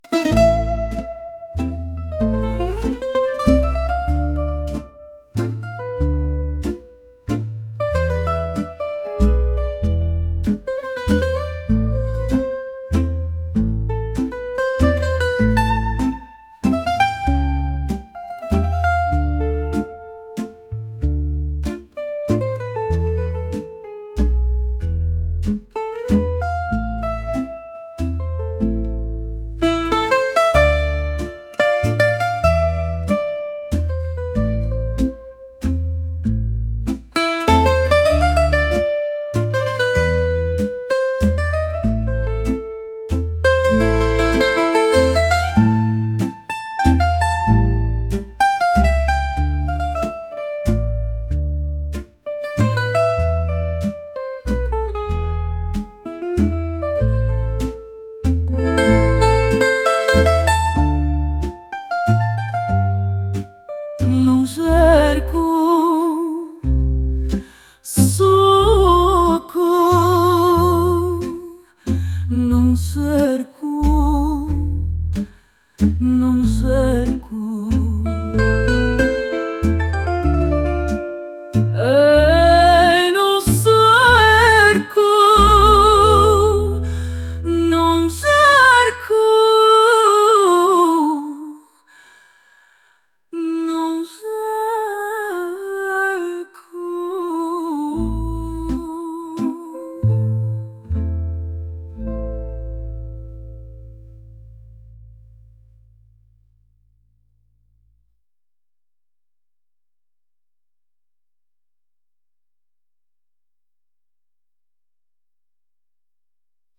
soulful